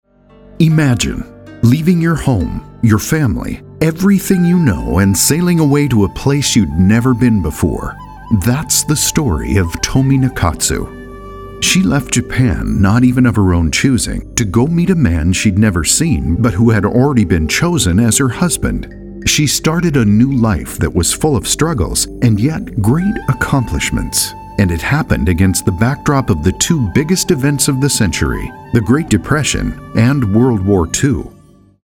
Warm, authentic, trustworthy, experienced English male voice.
Sprechprobe: eLearning (Muttersprache):